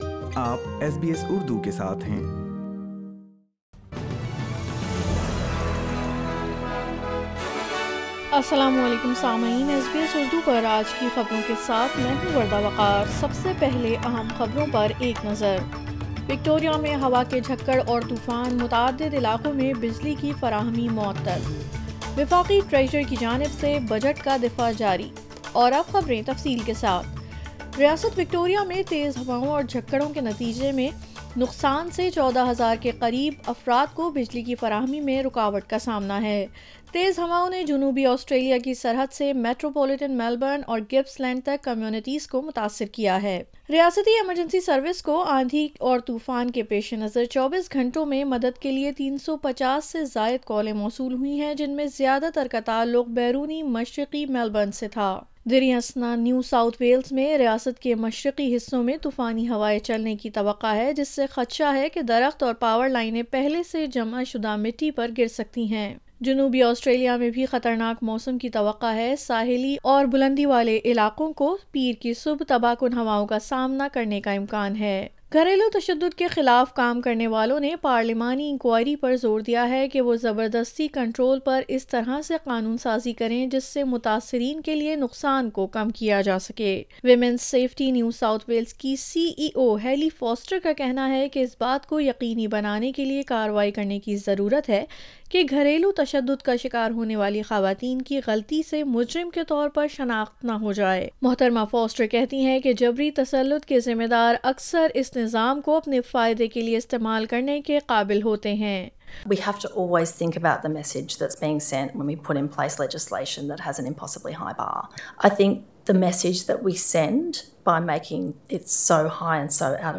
Urdu News Monday 31 October 2022